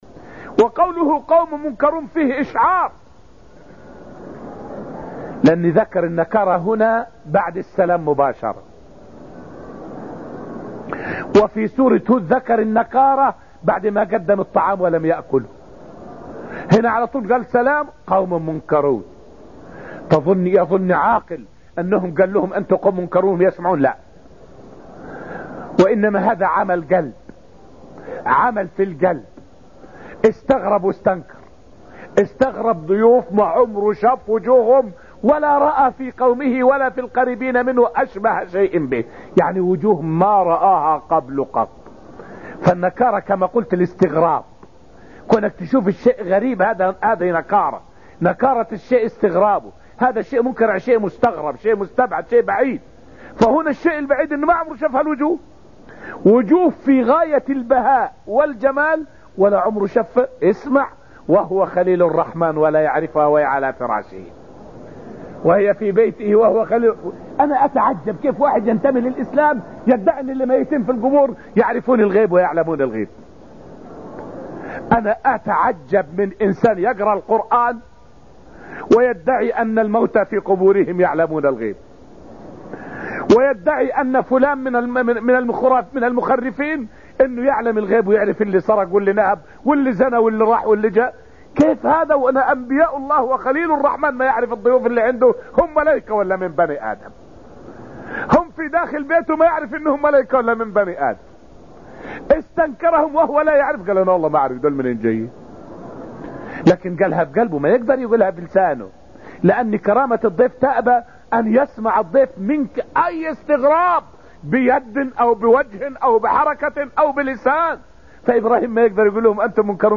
فائدة من الدرس الثاني من دروس تفسير سورة الذاريات والتي ألقيت في المسجد النبوي الشريف حول انفراد الله بعلم الغيب المطلق.